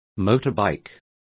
Complete with pronunciation of the translation of motorbikes.